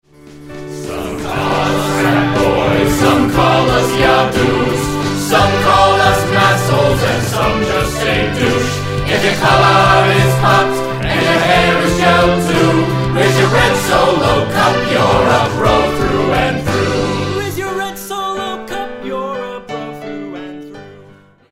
The songs have clever lyrics and melodies reminiscent of several different musical styles.
EXCERPTS FROM THE ORIGINAL MUSIC.